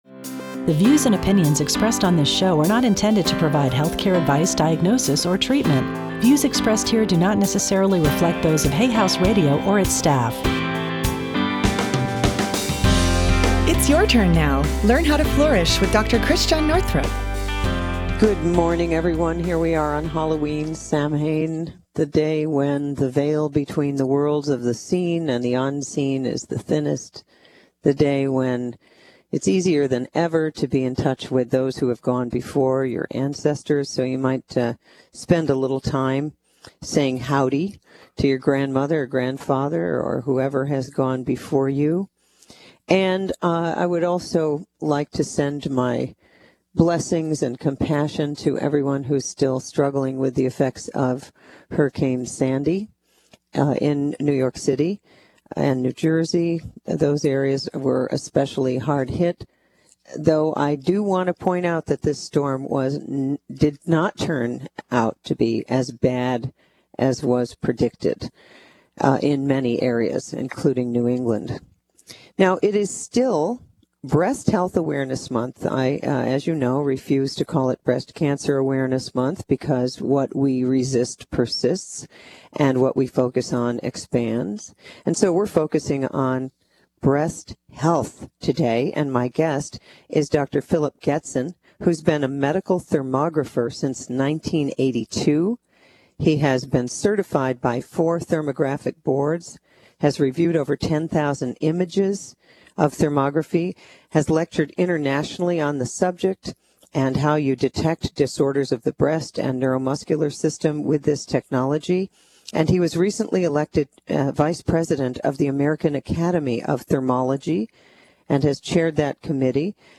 How to Prevent Breast Problems. Hay House Radio interview Track 01